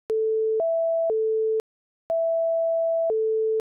This assignment is to make a Pd patch to play a simple melody that includes sliding pitches and continuously changing amplitudes.